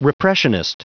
Prononciation du mot repressionist en anglais (fichier audio)
Prononciation du mot : repressionist